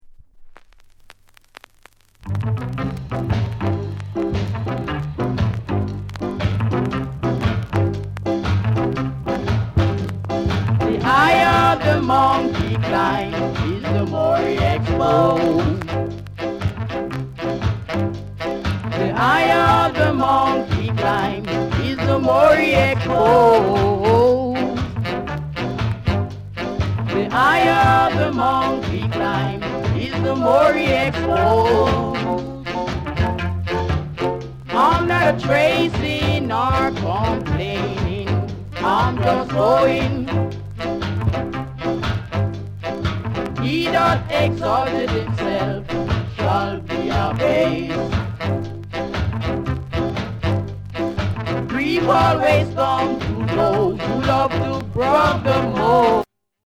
NICE SKA